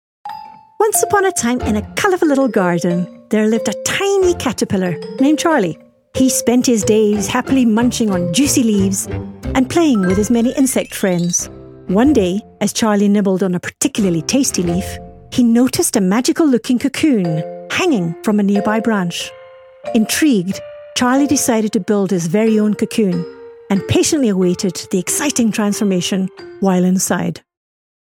authoritative, character, mature, nurturing, seasoned, wise
45 - Above
My demo reels